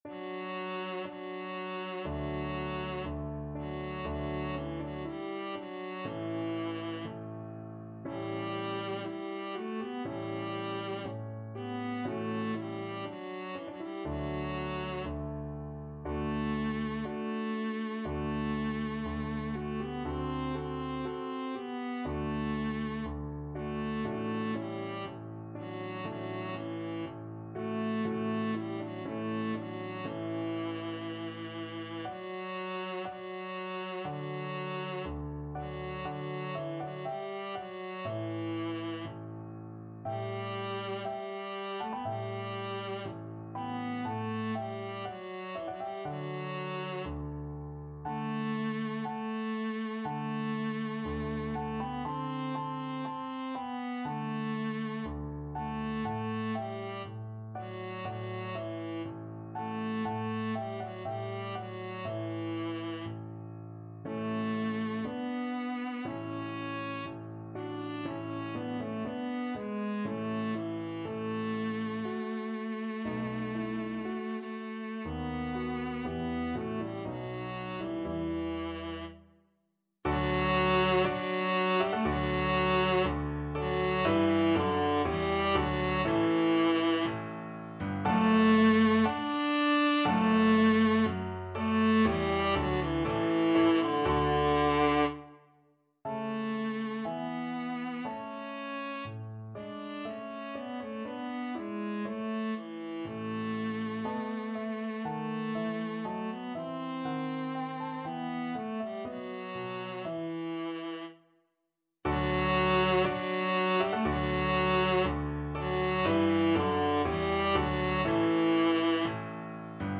Free Sheet music for Viola
Viola
4/4 (View more 4/4 Music)
D major (Sounding Pitch) (View more D major Music for Viola )
Slow =c.60
Classical (View more Classical Viola Music)
handel_saul_dead_march_VLA.mp3